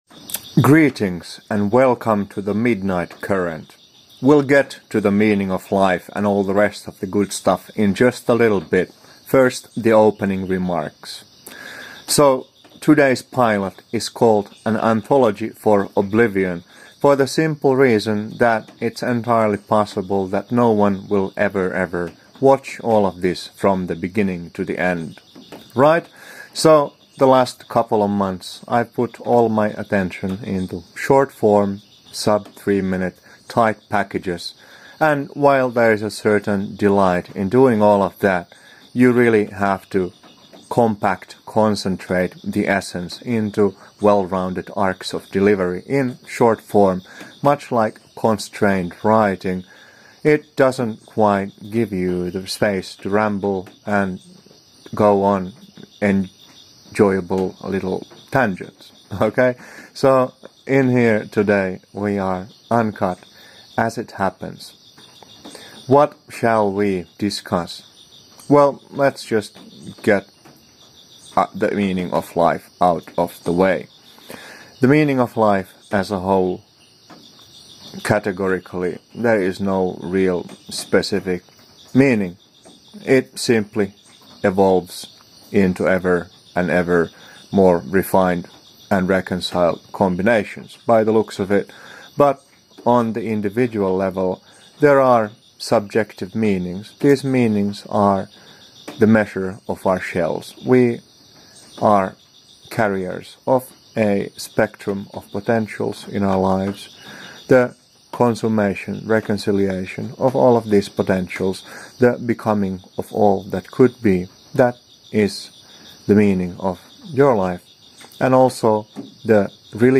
Broad spectrum of contemplations in one uncut stream. Into the meaning of life — and of death too.
We take some pauses here.